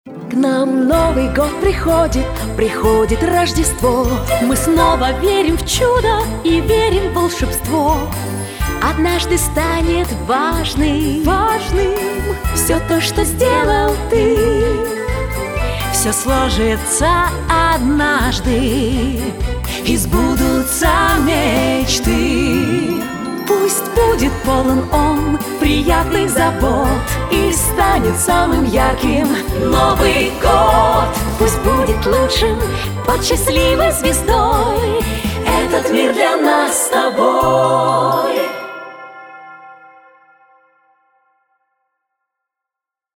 новогодней песни